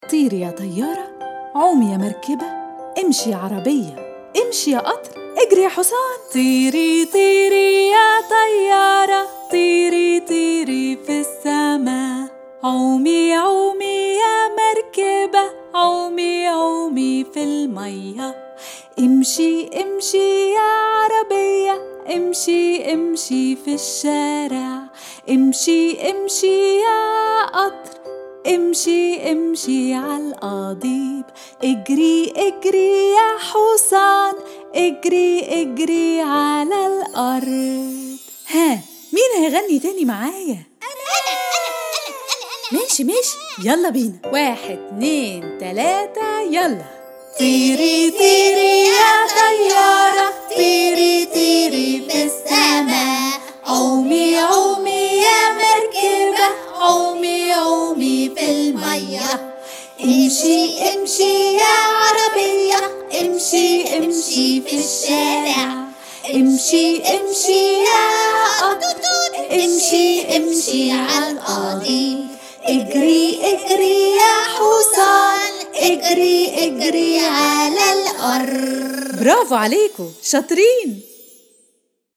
Their catchy rhythm and use of repetition gently boost their memory and vocabulary.